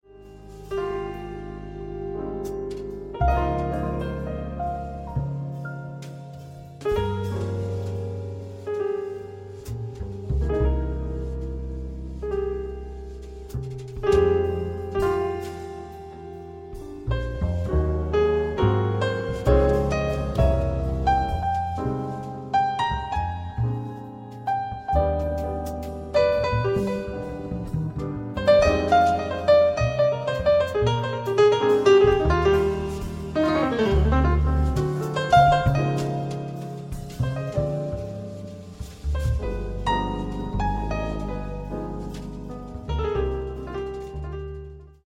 四、採用爵士樂中最受人喜愛的 鋼琴、鼓、貝斯 三重奏編制，演奏受人喜愛的旋律